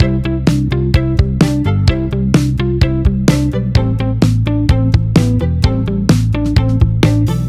こちらは比較的シンプルなアクセントのままの状態です。バックビートが当たり前となった今では、これは取り立てた特徴のないリズムと言えます。